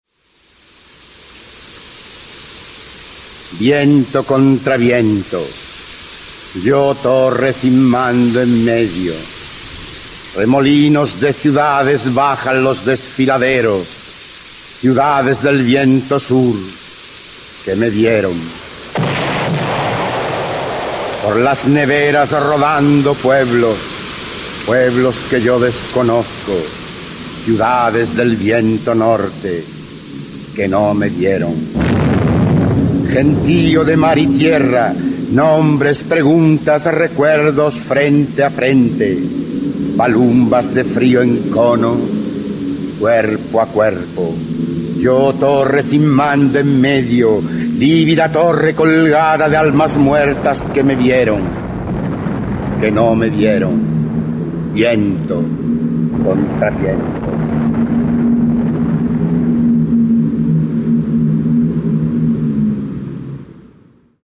Archivo de sonido con la voz del escritor español Rafael Alberti, quien recita su poema “Los ángeles bélicos" (Sobre los ángeles, 1927-1928).
Se recomienda este recurso para promover un encuentro placentero de los estudiantes con el texto literario, recitado en la voz de su autor.